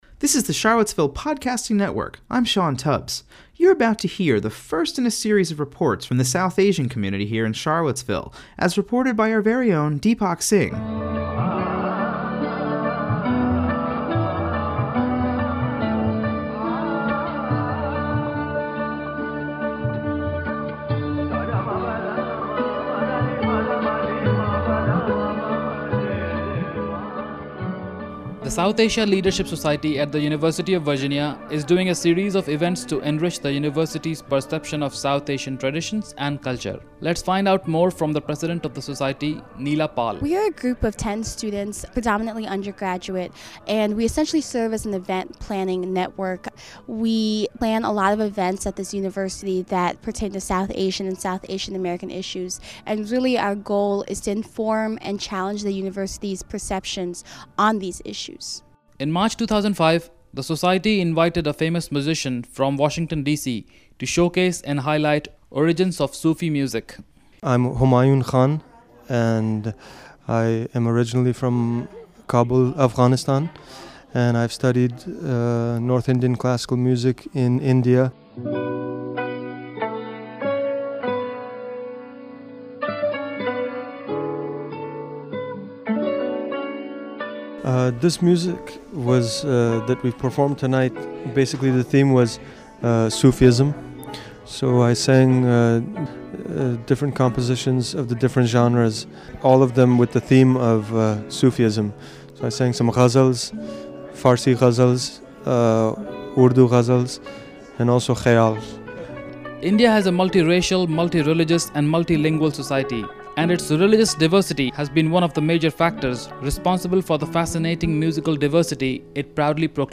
The Charlottesville Podcasting Network is proud to debut a new series of feature reports on the cultural and spiritual life of the South Asian community in Central Virginia.